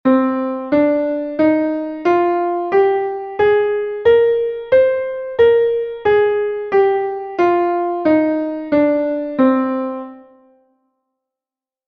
Do+Menor (audio/mpeg)